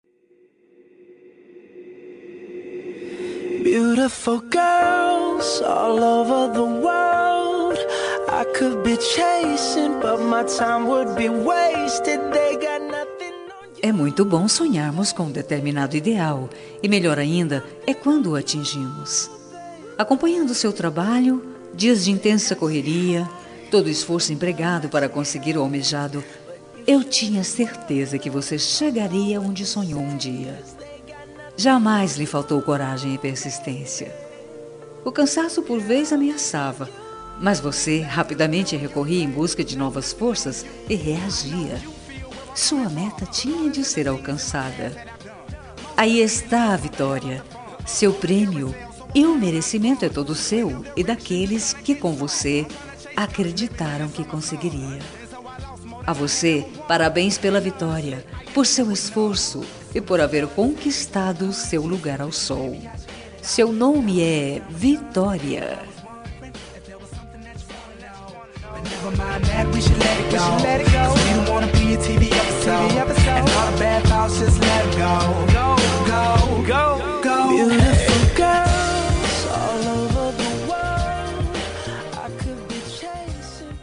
Telemensagem Conquista Vitória – Voz Feminina – Cód: 8147